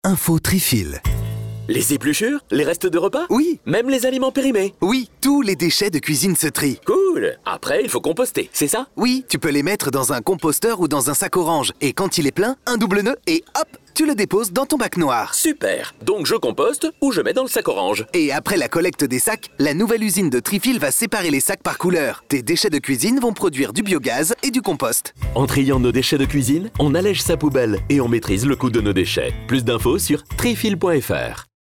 Affiche A3 Article Triscope Spot Radio